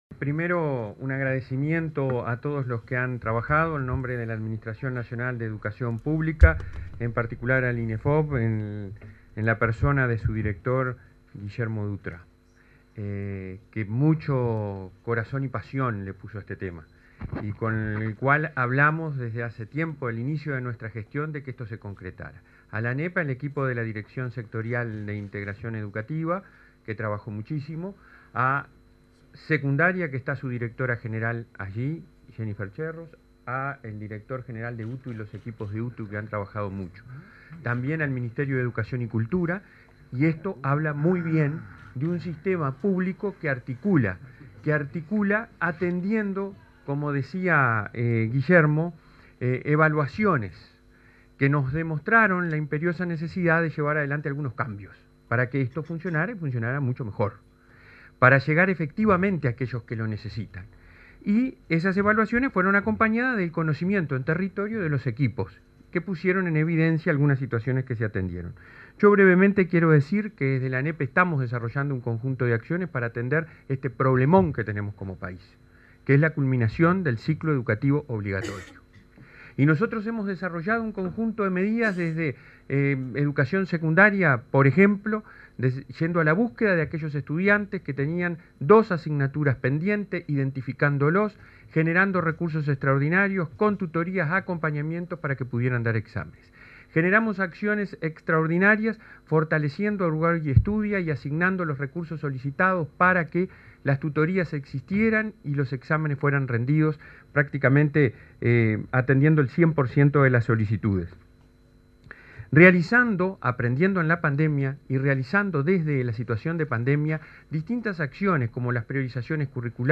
Ceremonia de firma de convenio y lanzamiento del programa Inefop Bachilleres 29/05/2023 Compartir Facebook X Copiar enlace WhatsApp LinkedIn En el marco de la ceremonia de firma de un convenio y el lanzamiento del programa Inefop Bachilleres, este 29 de mayo, se expresaron el presidente del Consejo Directivo Central (Codicen), de la Administración Nacional de Educación Pública (ANEP), Robert Silva, y los ministros de Trabajo y Seguridad Social, Pablo Mieres, y Educación y Cultura, Pablo da Silveira.